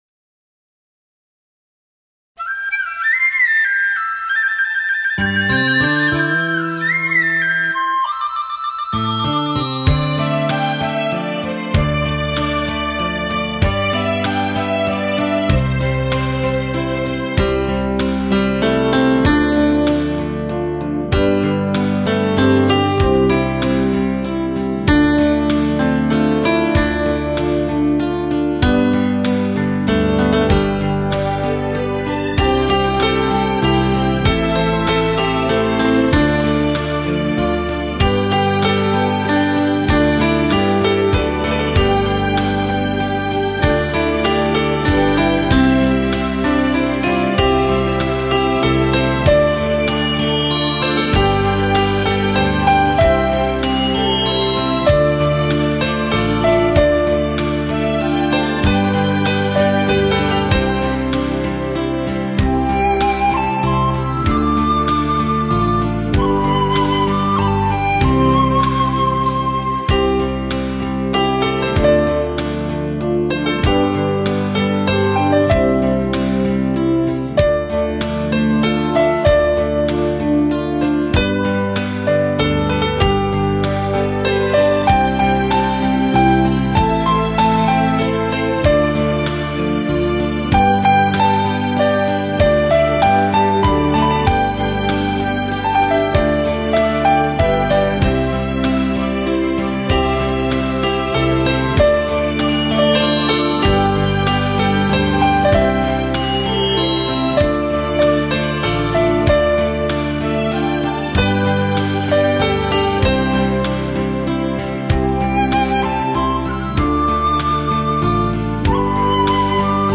钢琴